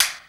perc_16.wav